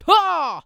CK发力06.wav
人声采集素材/男2刺客型/CK发力06.wav